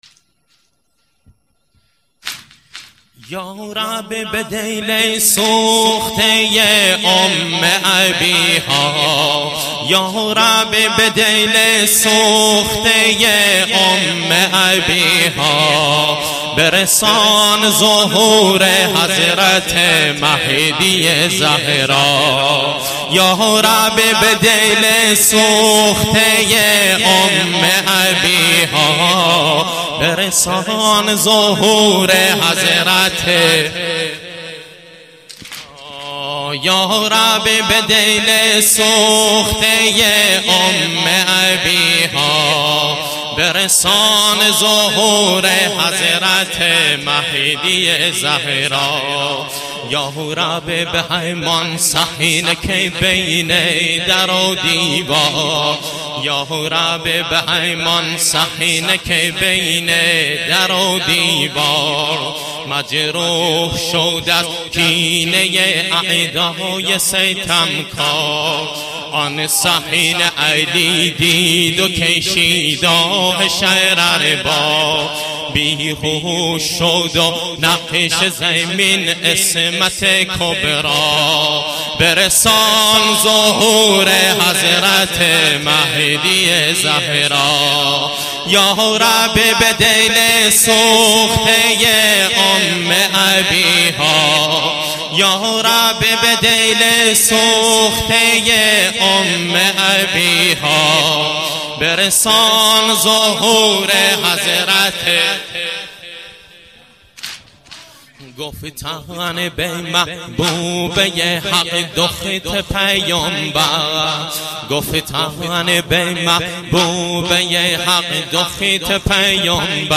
واحد - یارب به دل سوخته